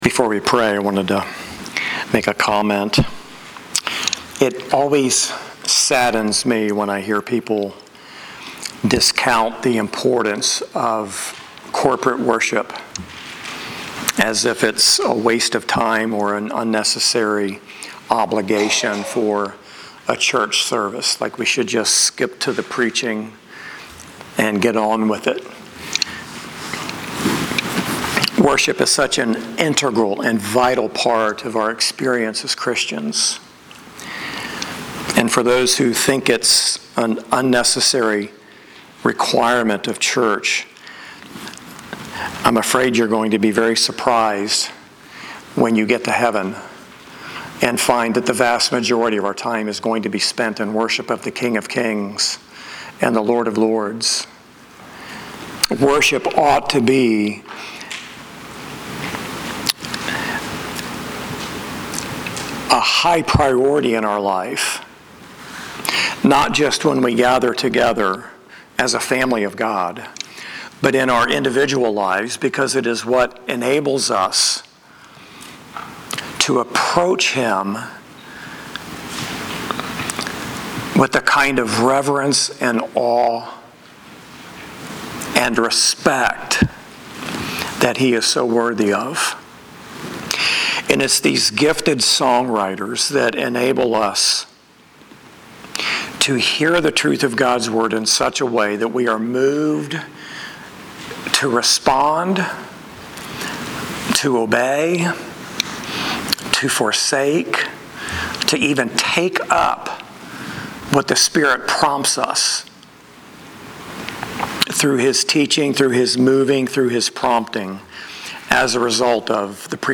8-31-25-Sermon.mp3